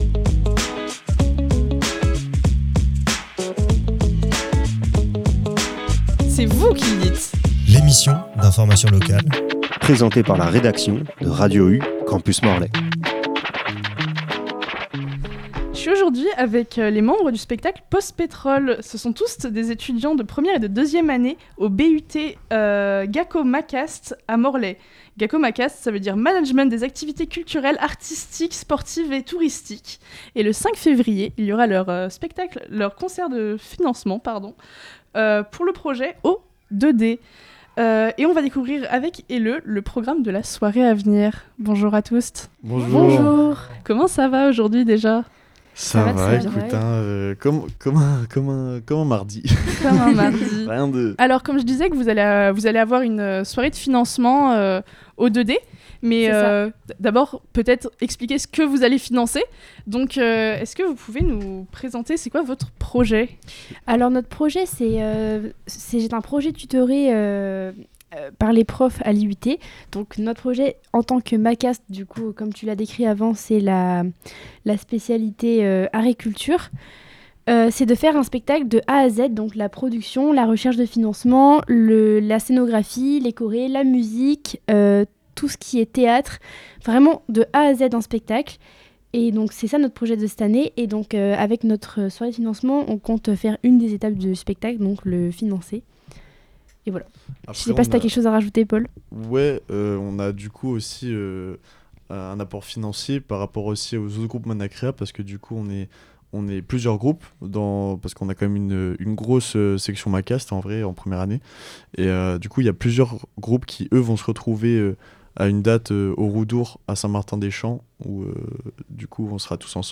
On accueille des étudiants et étudiantes de première et deuxième année du BUT GACO MACAST (Management des Activités Culturelles, Artistiques, Sportives et de Tourisme) de l’IUT de Morlaix, pour parler de leur projet "Post Petrol" dans le cadre du festival RESSAC. On parle aussi de leur concert de financement qui aura lieu au 2D à Morlaix ce jeudi 5 février 2026.